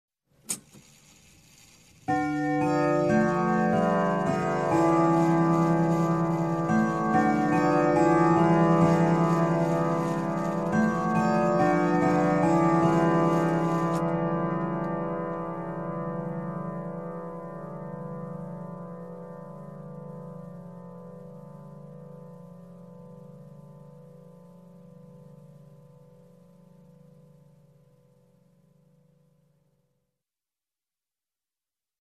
Звук часов бьющих без четверти
Часы, бой часов
Часы бьют без четверти